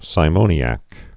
(sī-mōnē-ăk, sĭ-)